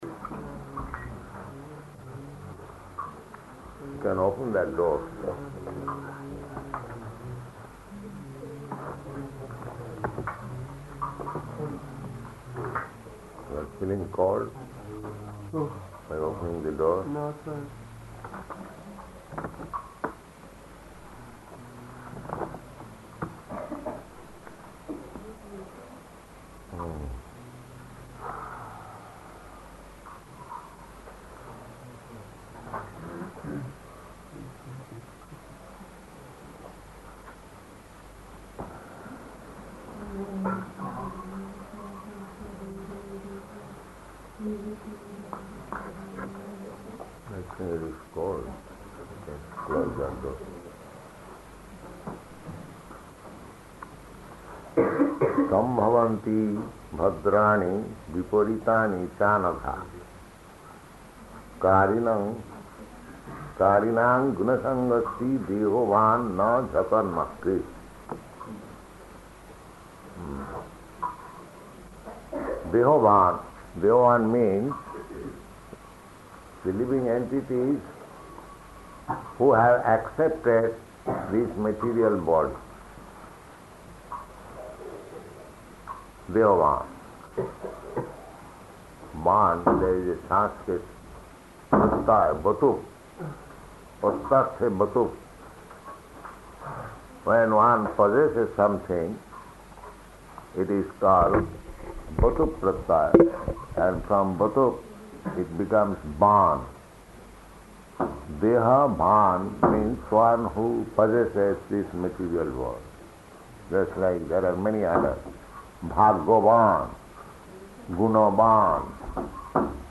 Śrīmad-Bhāgavatam 6.1.44 --:-- --:-- Type: Srimad-Bhagavatam Dated: December 25th 1970 Location: Surat Audio file: 701225SB-SURAT.mp3 Prabhupāda: You can open that door.